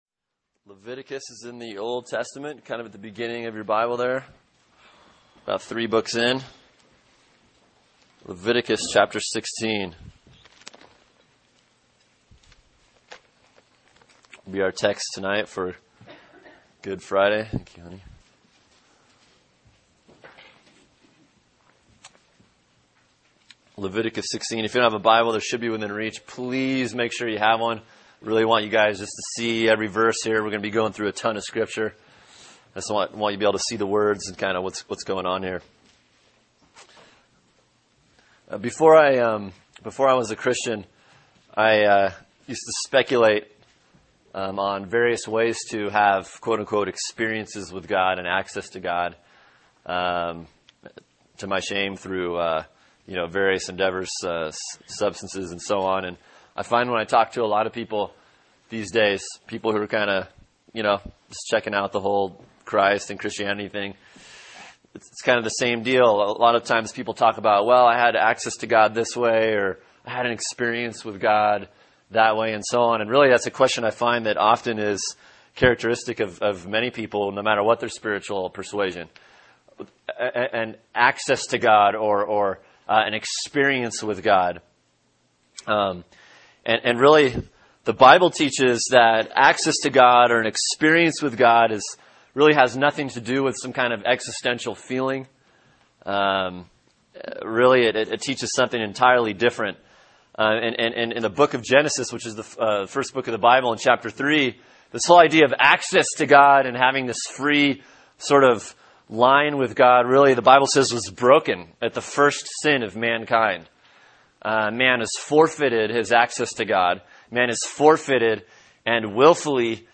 Sermon: Leviticus 16 “Good Friday” | Cornerstone Church - Jackson Hole